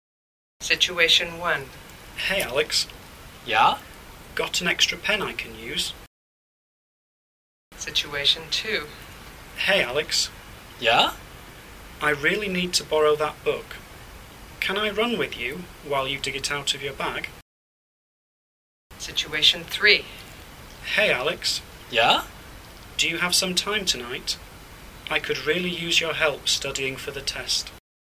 Ch4 " Follow-up Activity 4 - Slow - No Repeat.mp3
Ch4-Follow-upActivity-Slow-NoRepeat.mp3